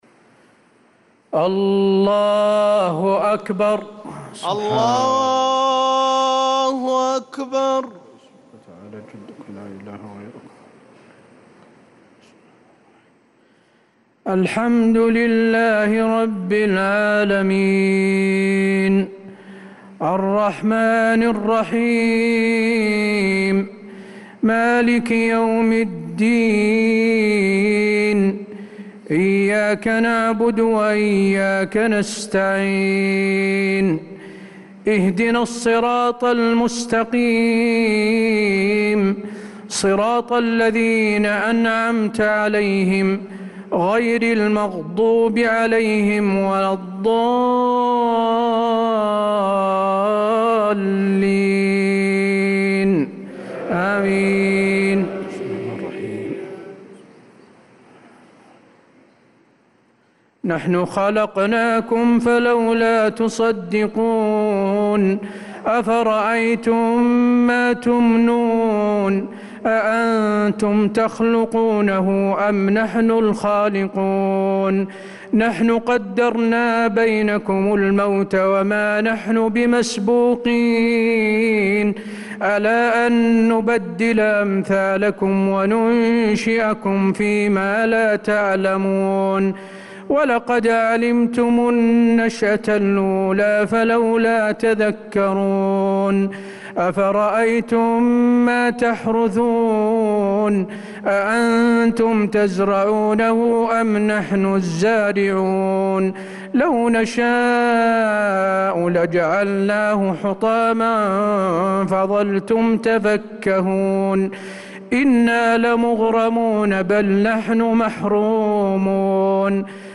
صلاة العشاء للقارئ حسين آل الشيخ 26 محرم 1446 هـ
تِلَاوَات الْحَرَمَيْن .